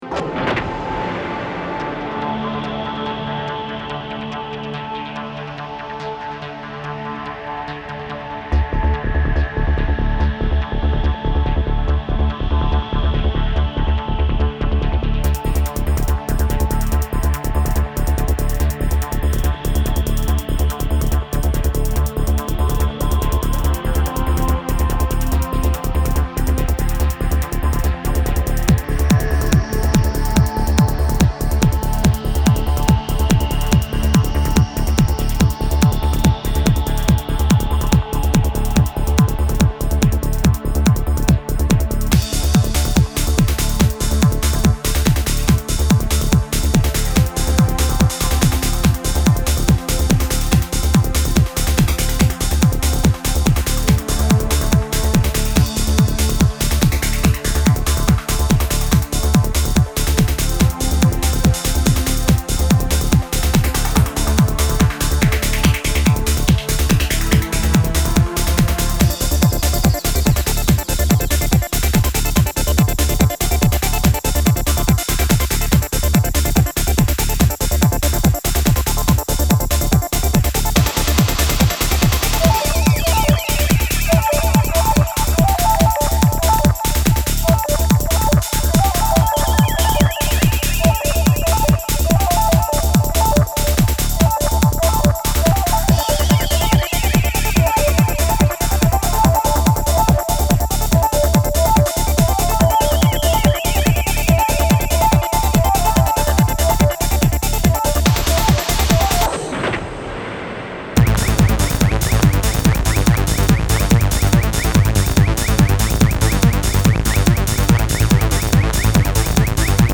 и исполняющий электронную музыку с элементами world music.